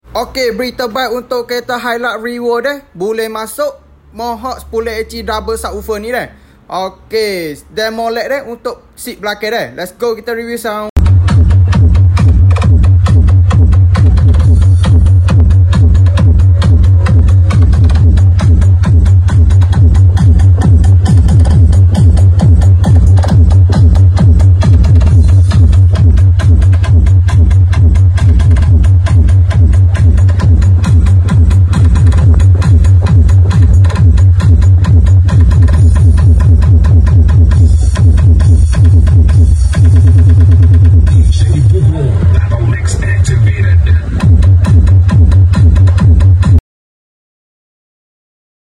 Double Subwoofer